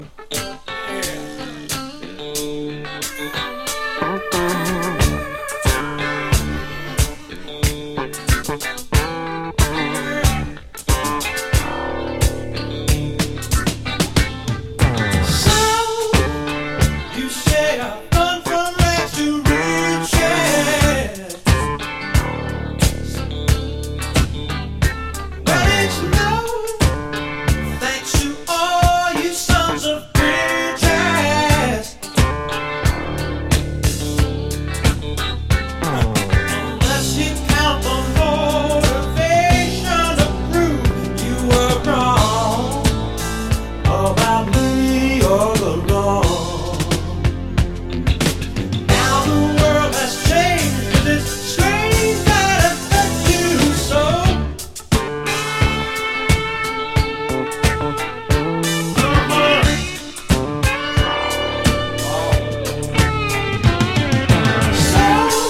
ミディアムAORメロウ・ブギー